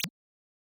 generic-select-softer.wav